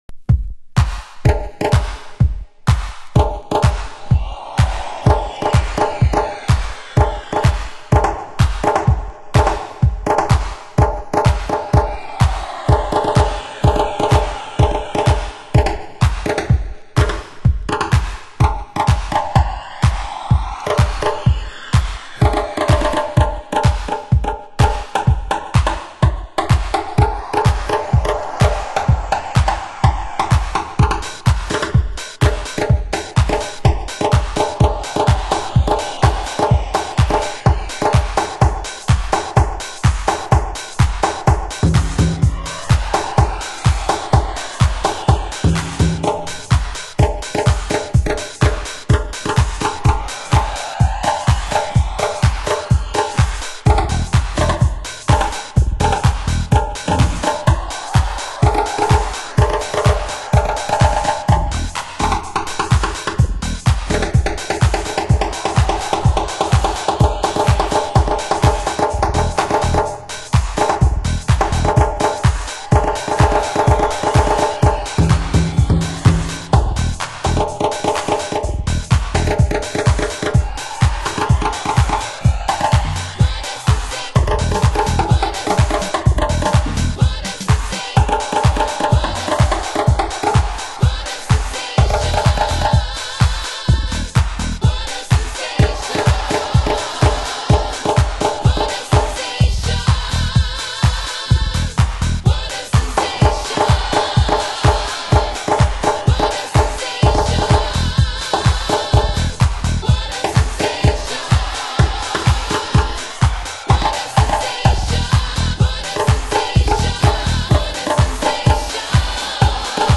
盤質：B面に少しチリパチノイズ 有　　　　ジャケ：シュリンク残（部分的な破れ有）